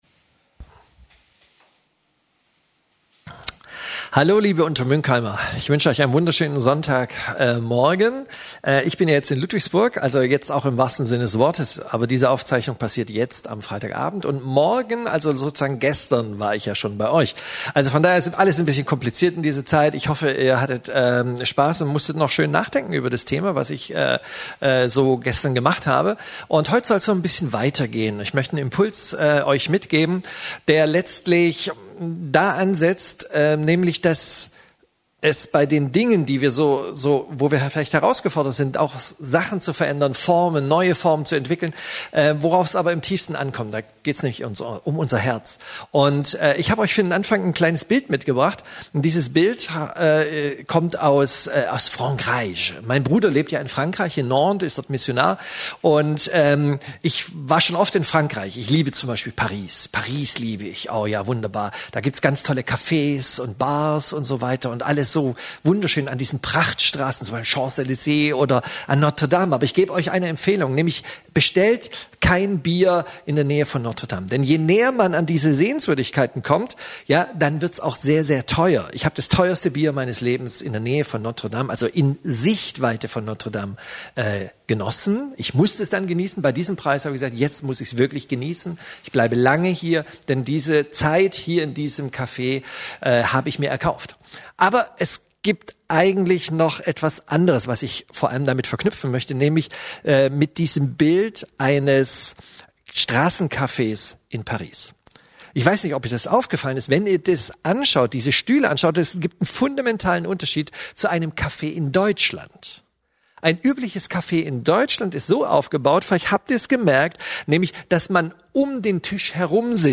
Tiefer blicken – Predigten: Gemeinschaftsgemeinde Untermünkheim